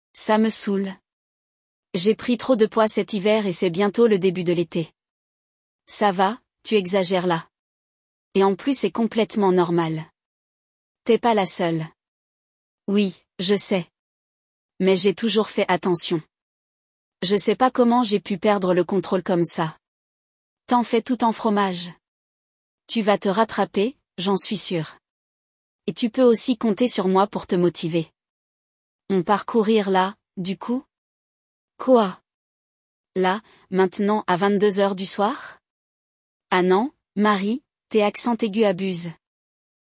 Aujourd´hui, nous allons nous plonger dans une conversation entre deux amies dont l’une se plaint d’avoir pris du poids.
Nesta conversa informal, você vai receber dicas de pronúncia sobre como os franceses encurtam as palavras ou como eles “comem” algumas letras.